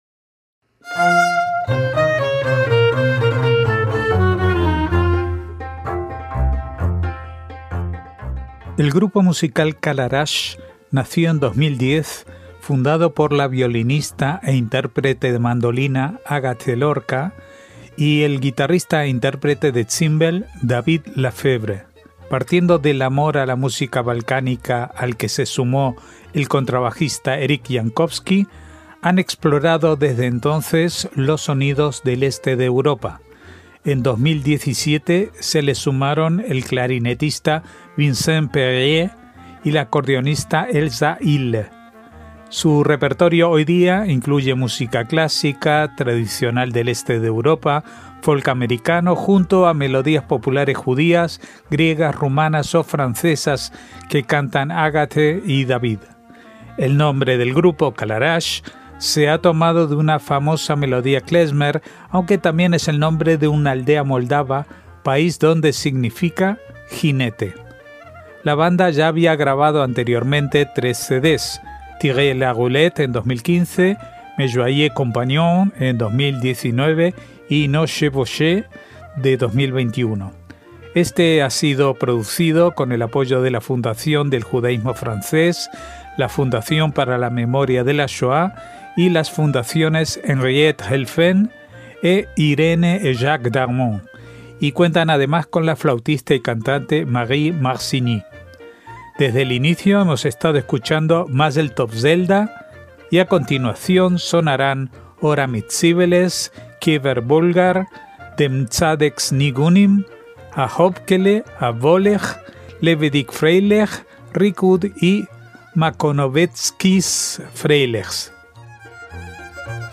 Kalarash: música klezmer, ídish y chansons francesas